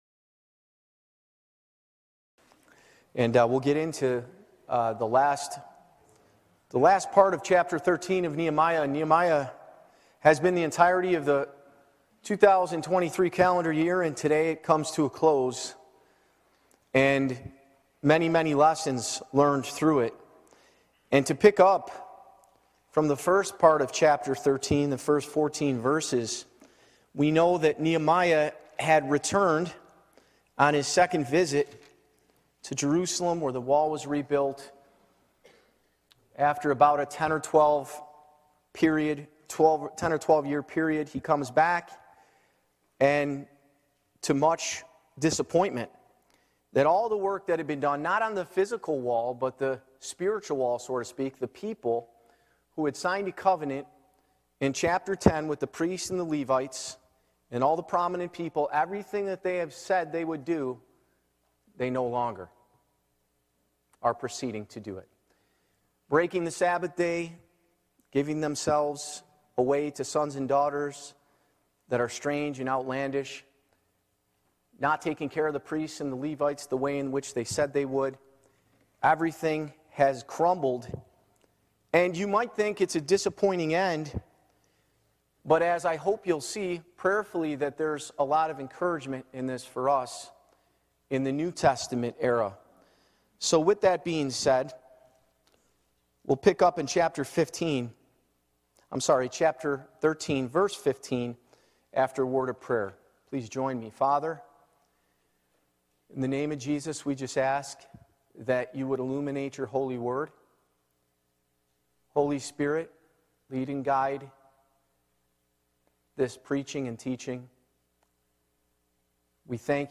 | Nehemiah 13:15-31 Live Recording Date: Sunday, June 11, 2023 Episide 129 Audio (MP3) Previous I Know Where Satan’s Seat Is | Nehemiah 13:1-14 Next Father's Day 2023 - Follow Me